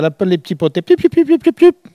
Mémoires et Patrimoines vivants - RaddO est une base de données d'archives iconographiques et sonores.
Elle crie pour appeler les canetons